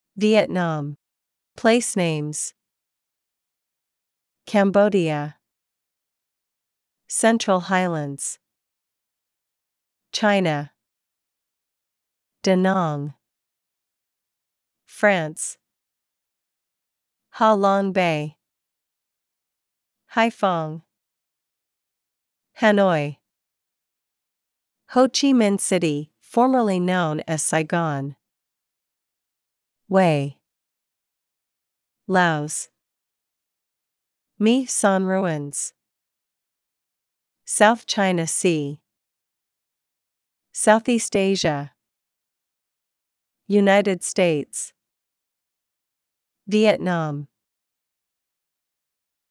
• Three MP3s: A narration about the country, a pronunciation guide to key place names, and a factual Q&A segment.
sample-vietnam-place-names.mp3